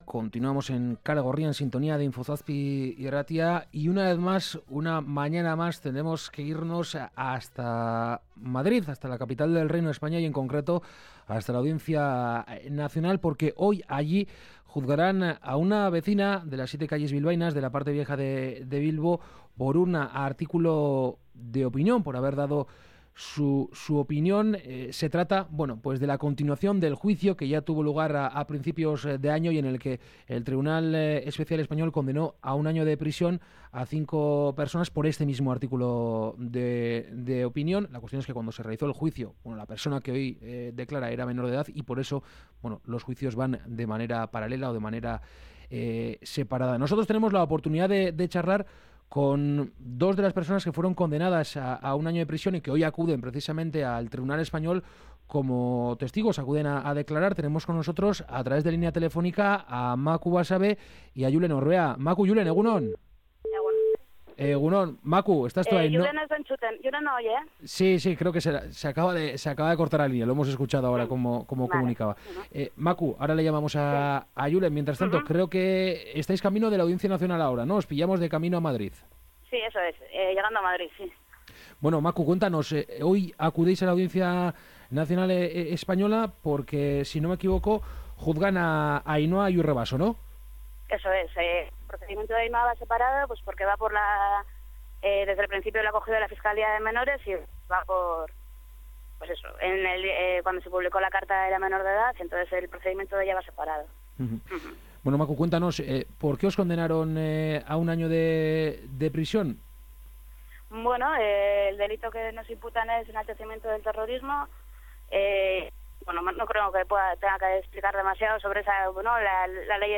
Entrevista política